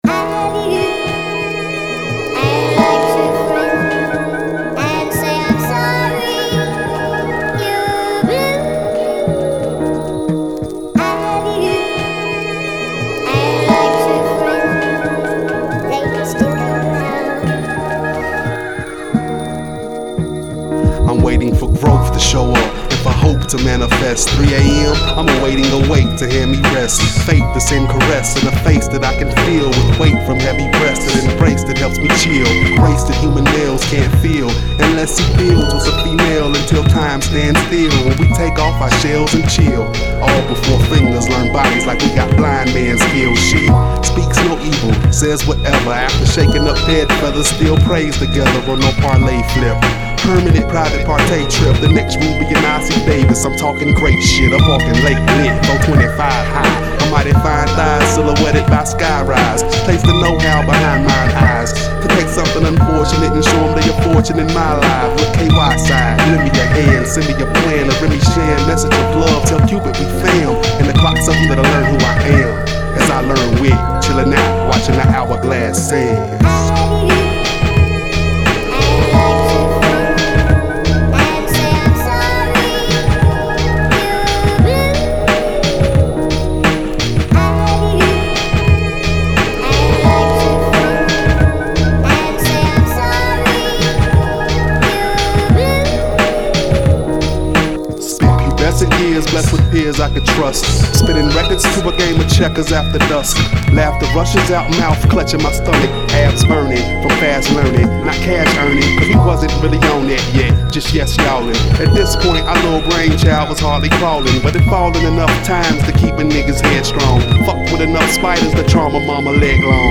Southern trio